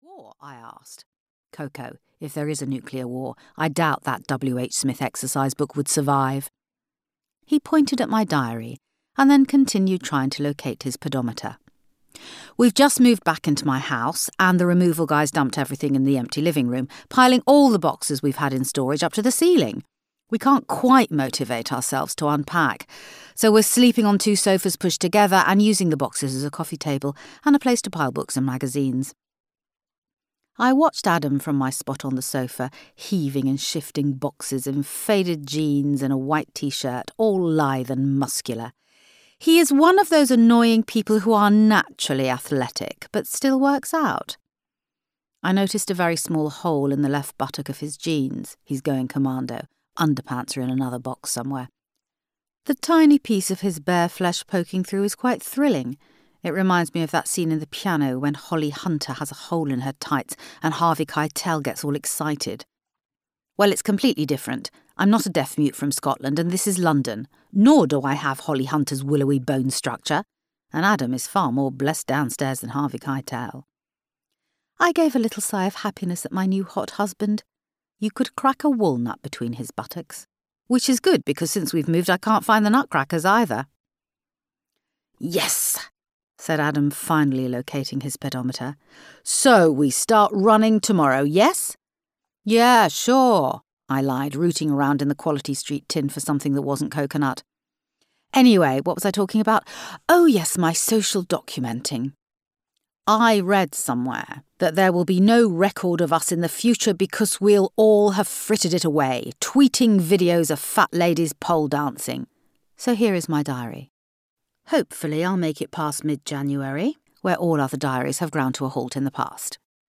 Audio knihaCoco Pinchard, the Consequences of Love and Sex
Ukázka z knihy